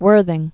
(wûrthĭng)